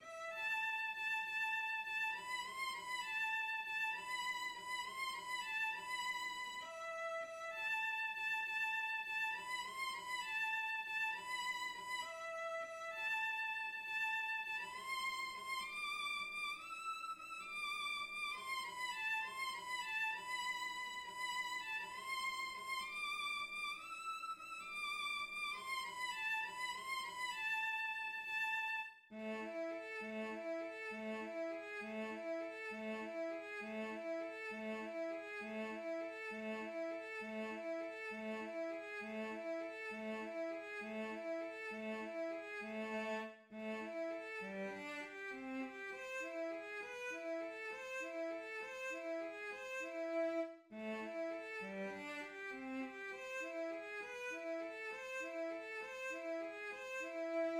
Für zwei Gitarren